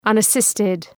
Προφορά
{,ʌnə’sıstıd}